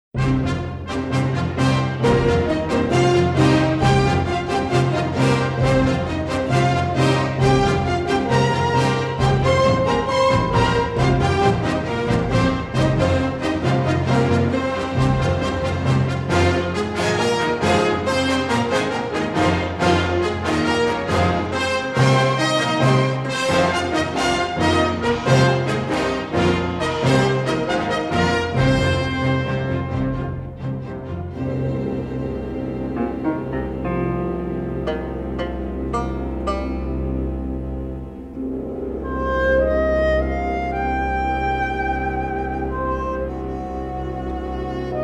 each filled with exotic melody and instrumentation.
galloping passages
with saxophone, harpsichord and flute elegantly spotlighted.
remixed in stereo from the original three-track masters.